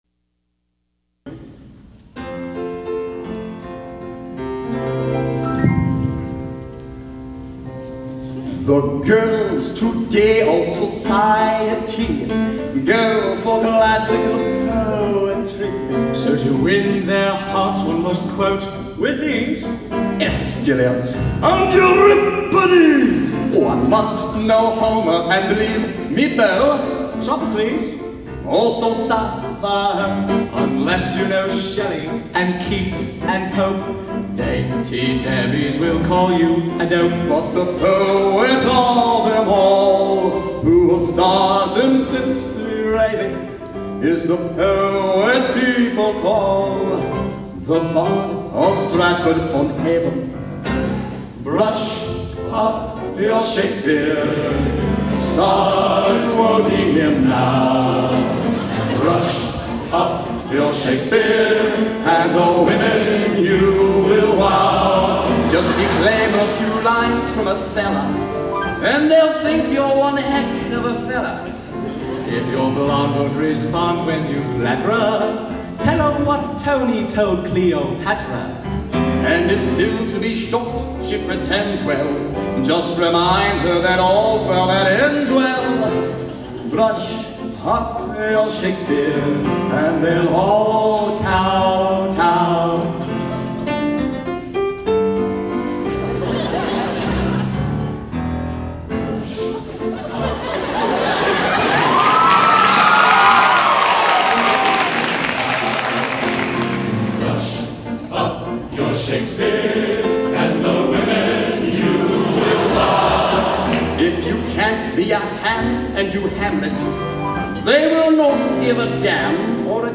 Genre: Broadway | Type: